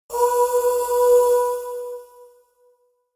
軽やかで明るい女性のハミングが、通知音としてポジティブなエネルギーを届けます。